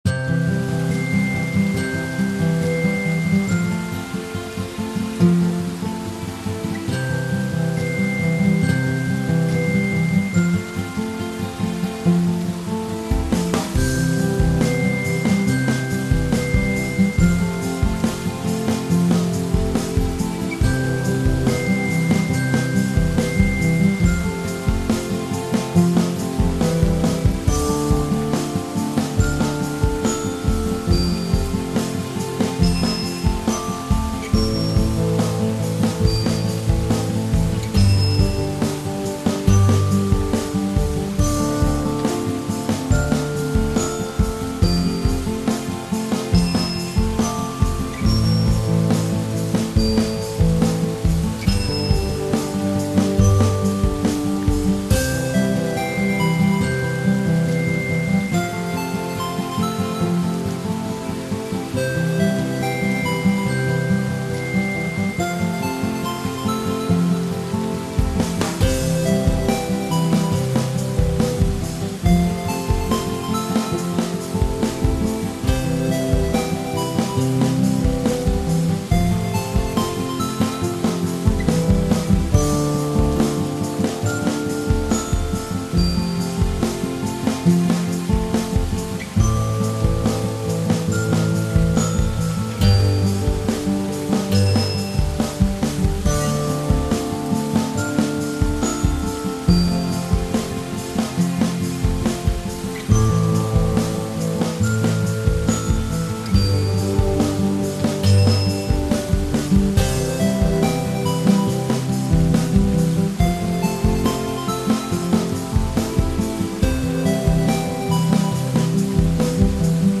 Guitars
Strings/Bells/8-Bit
It's instrumental.